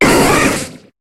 Cri de Boustiflor dans Pokémon HOME.